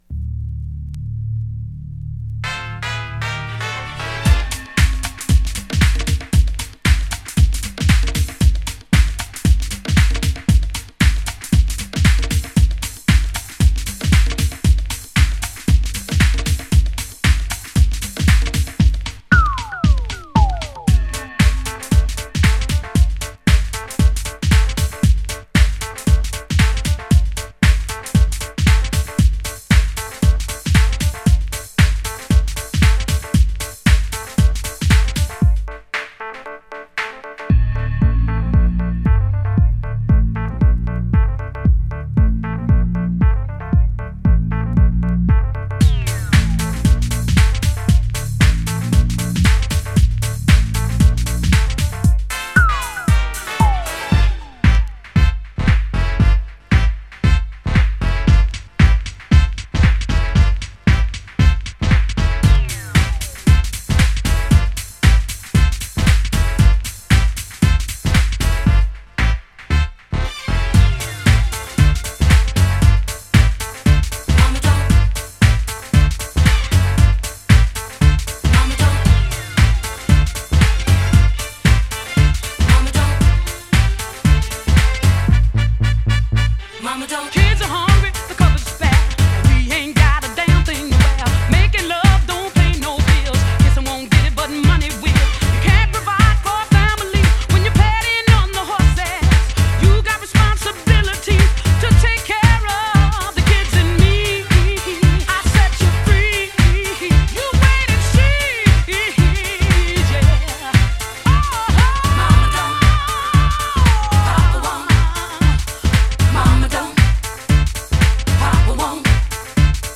DISCO
HOUSE REMIX !!